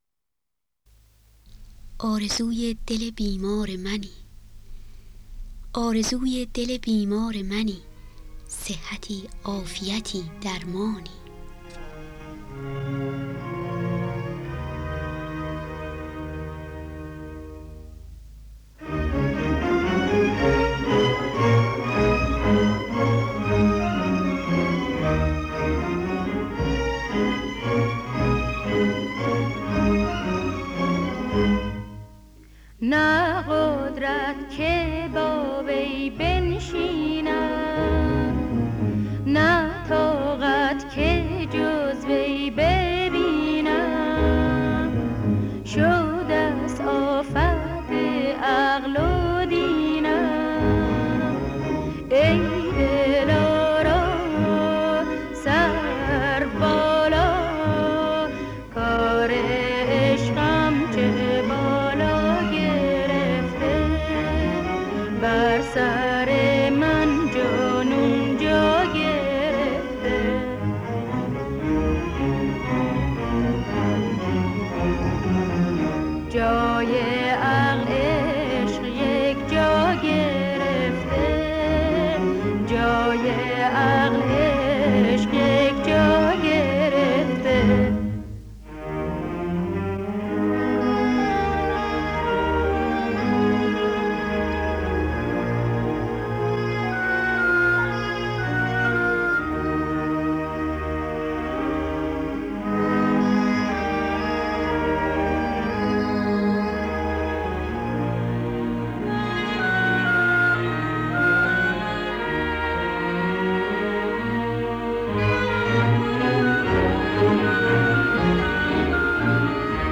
با صدای بانوان